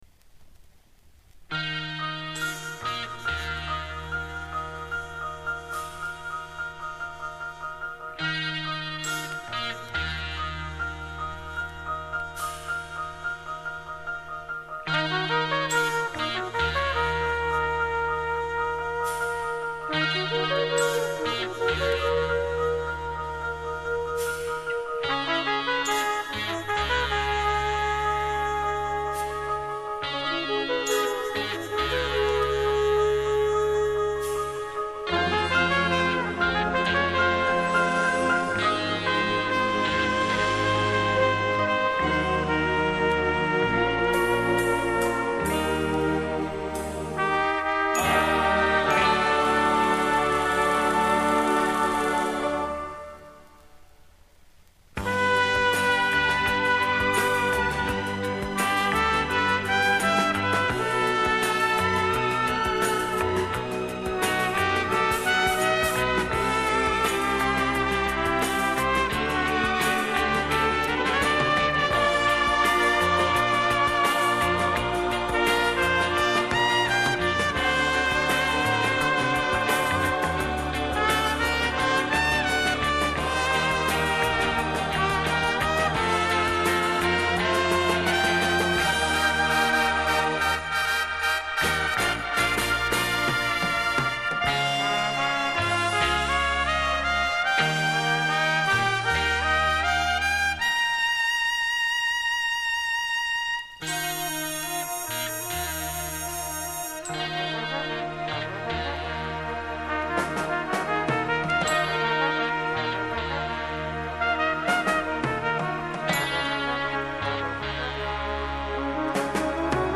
Музыкант-трубач из Чехословакии